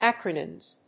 /ˈækrənɪmz(米国英語)/